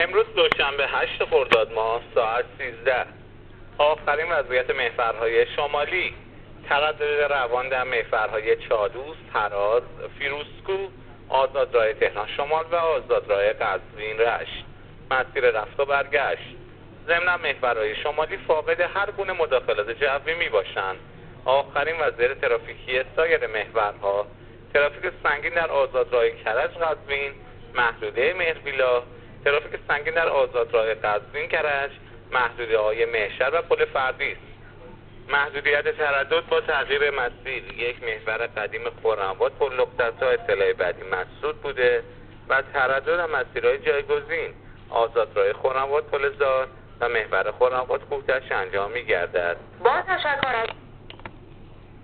گزارش رادیو اینترنتی از آخرین وضعیت ترافیکی جاده‌ها تا ساعت ۱۳ هشتم خرداد؛